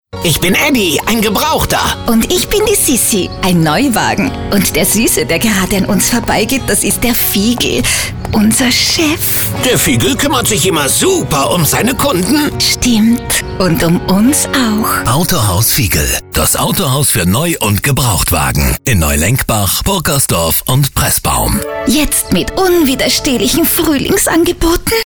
Sprechprobe: Sonstiges (Muttersprache):
Austrian voice over artist with professional studio. My voice is smooth, warm, friendly, solid, confident and trustable.